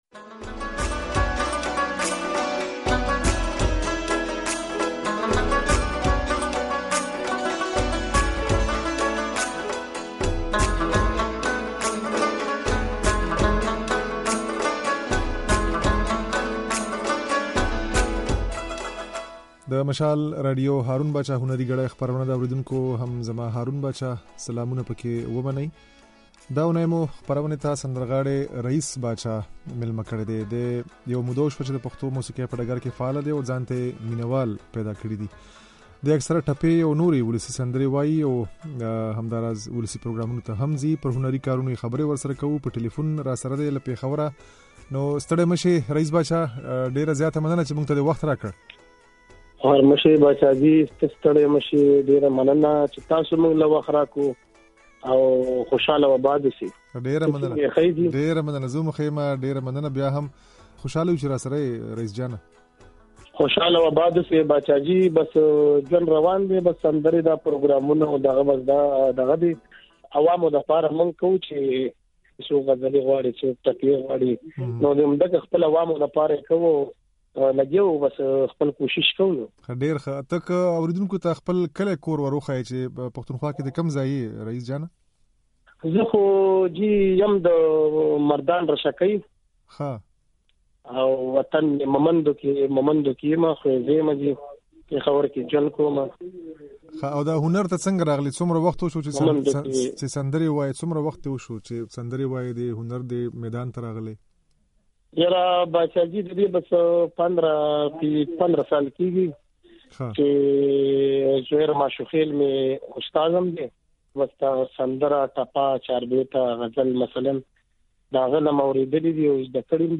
د نوموړي دا خبرې او څو سندرې يې د غږ په ځای کې اورېدای شئ.